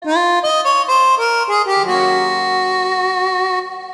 Sms сообщение
печальная мелодия